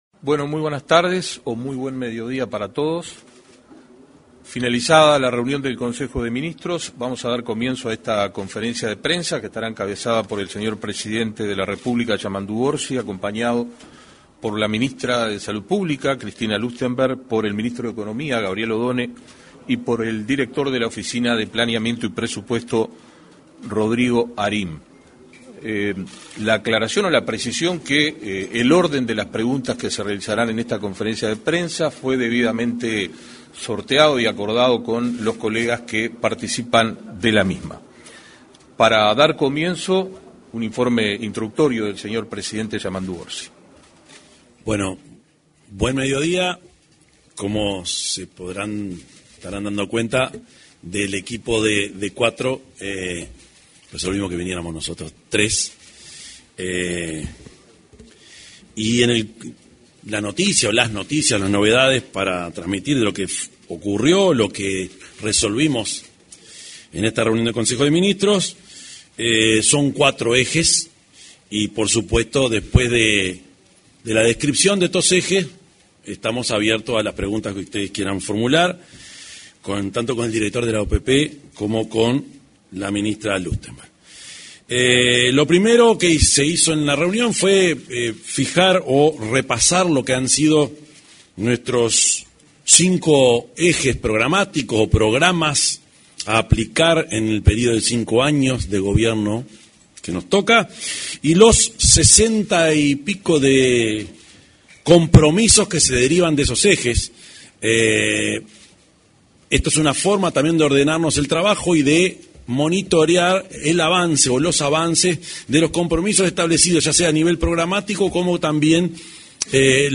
Conferencia de prensa - Consejo de Ministros
Conferencia de prensa - Consejo de Ministros 25/03/2025 Compartir Facebook X Copiar enlace WhatsApp LinkedIn Este martes 25 se realizó una conferencia de prensa, luego de finalizado el Consejo de Ministros. En la oportunidad se expresó el presidente de la República, profesor Yamandú Orsi; la ministra de Salud Pública, Cristina Lustemberg, y el director de la Oficina de Planeamiento y Presupuesto, Rodrigo Arim.